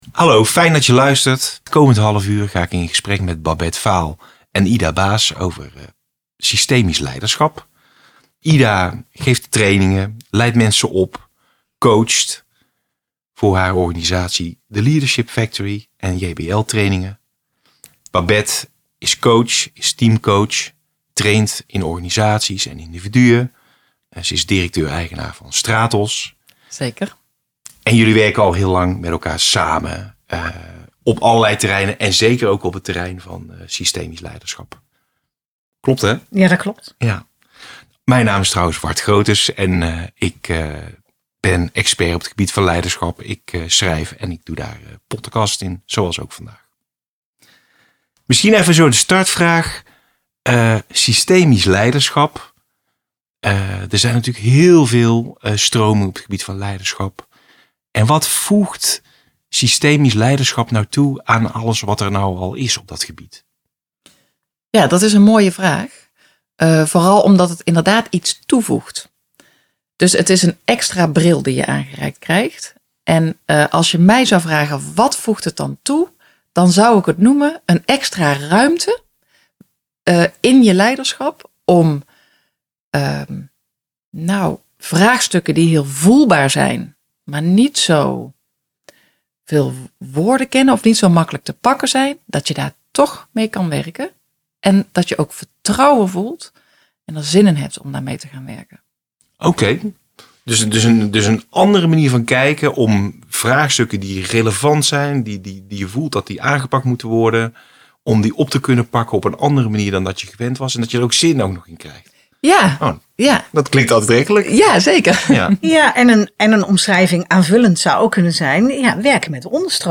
in gesprek over Systemisch Leiderschap